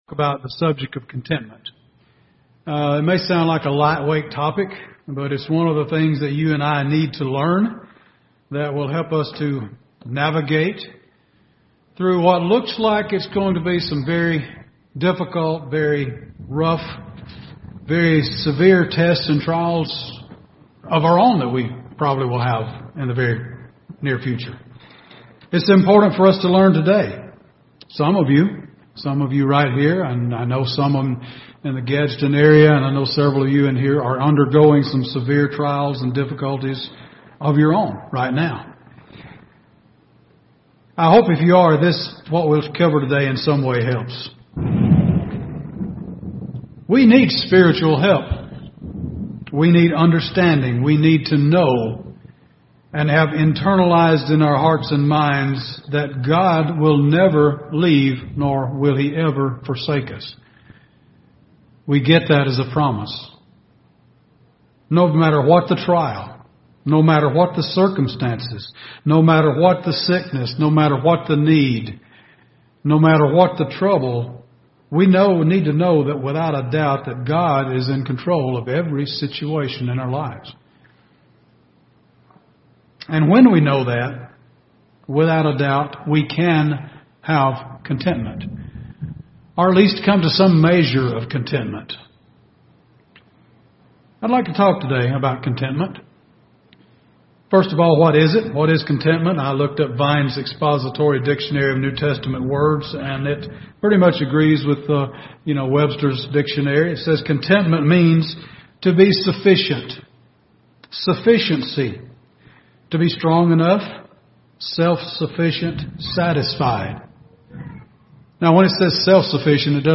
Given in Birmingham, AL Gadsden, AL
UCG Sermon Studying the bible?